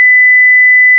radar_flat.wav